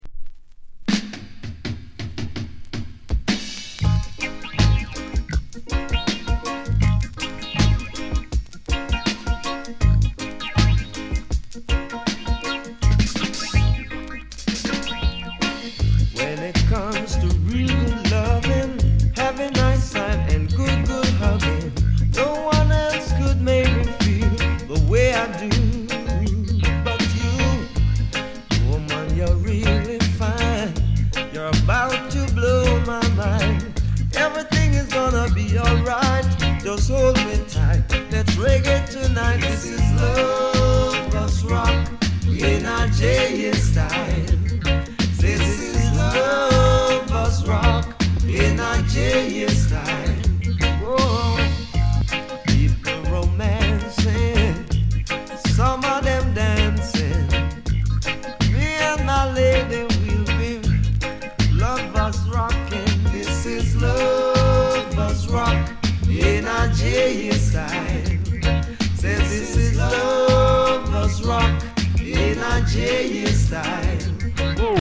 US 高音質